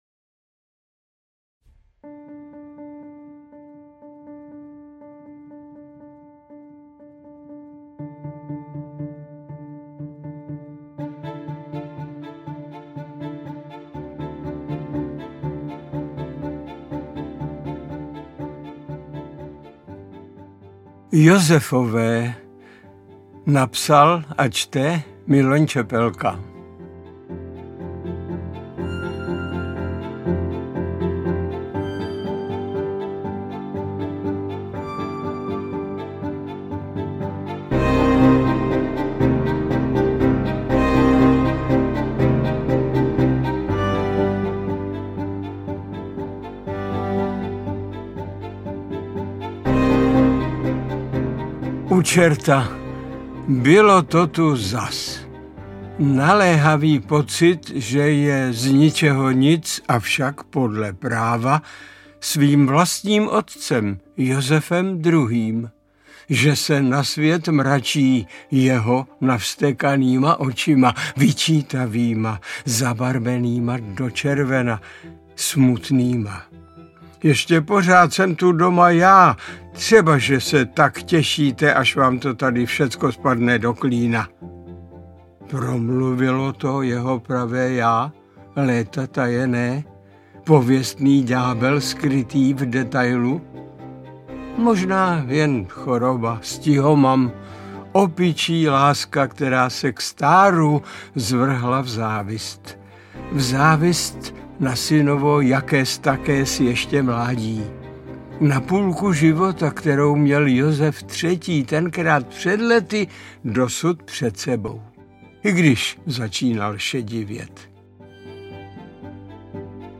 Interpret:  Miloň Čepelka